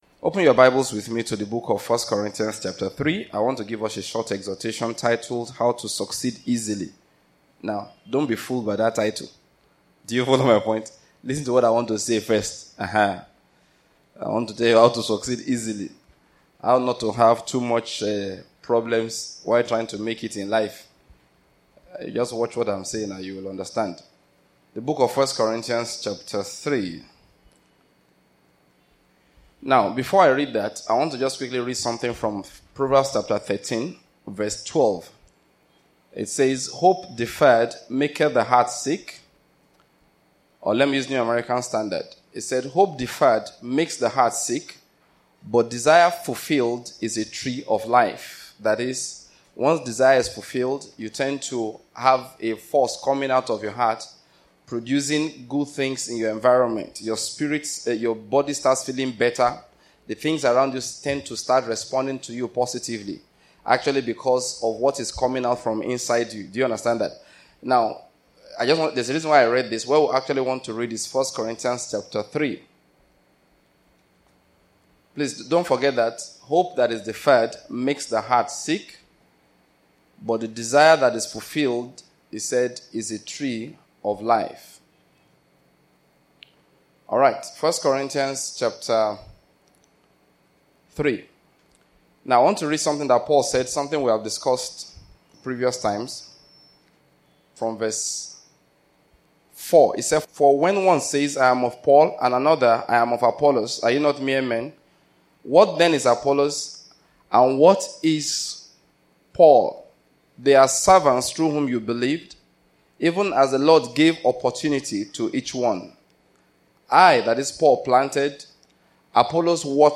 Succeeding With Ease and other sermons